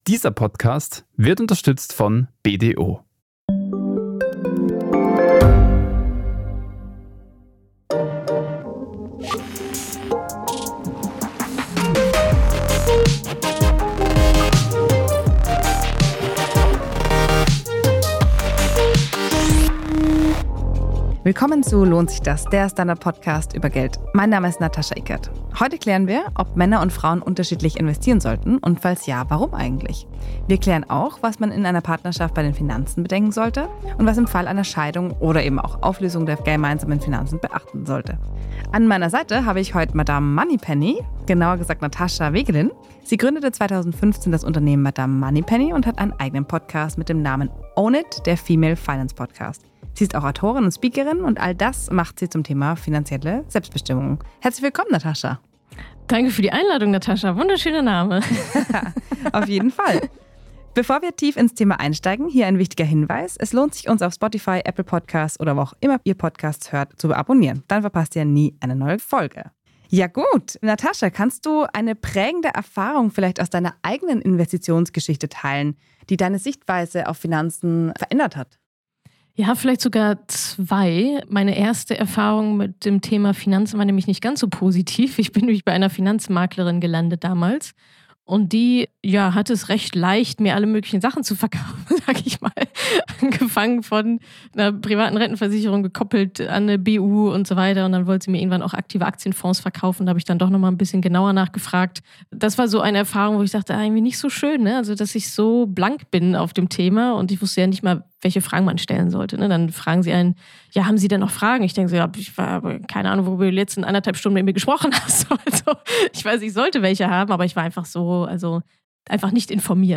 ins Podcaststudio eingeladen. Sie erzählt von ihrer Recherche zur Airbnb-Vermietung und welche Einschränkungen nun gelten.